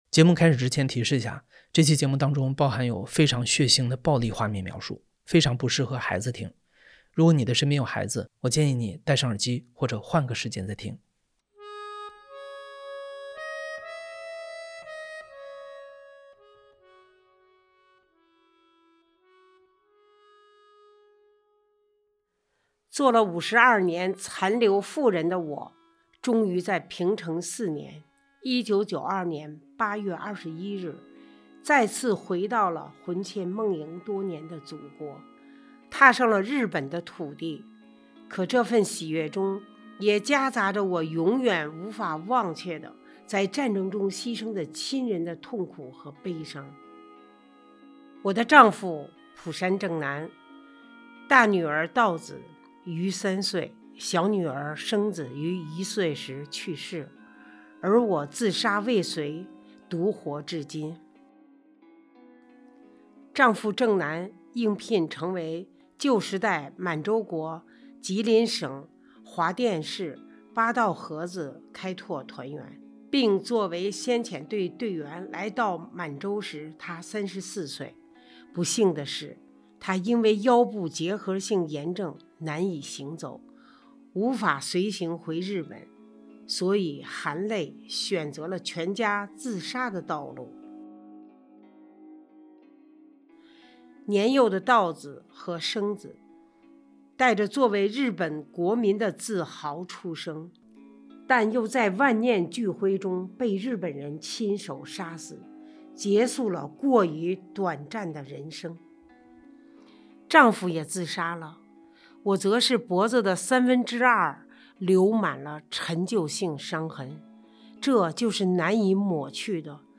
2025年度大型系列声音纪录片
故事FM 是一档亲历者自述的声音节目。